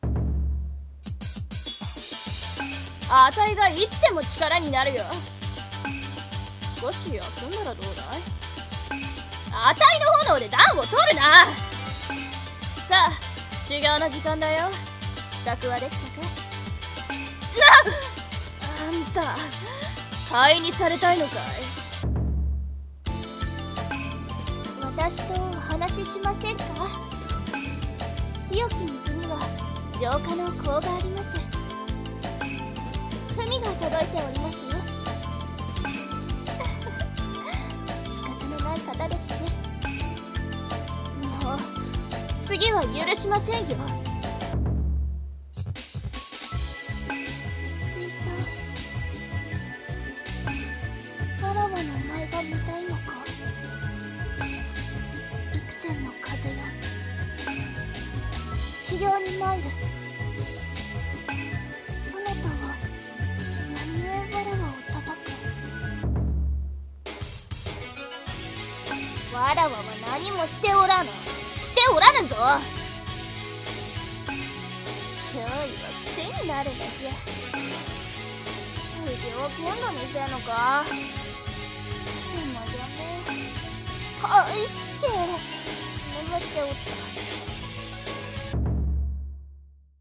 妖怪格ゲーアプリ 女声/少年声用